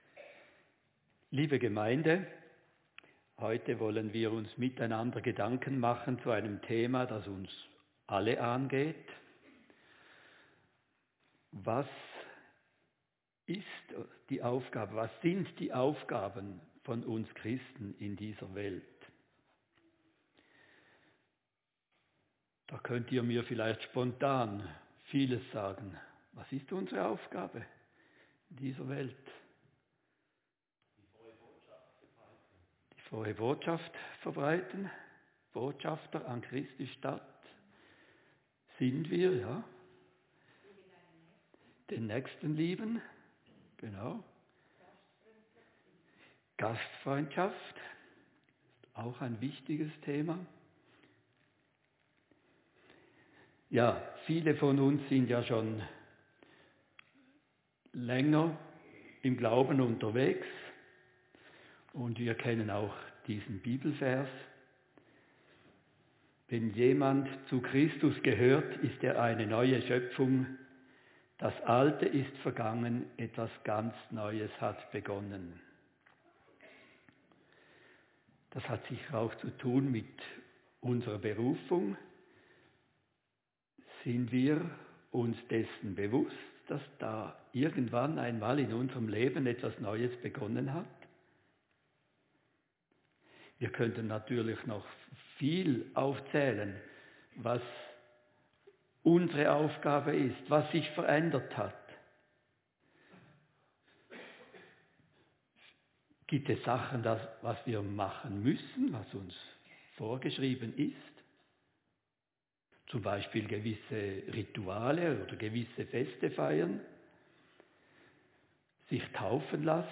Predigt-2.-Maerz-25.mp3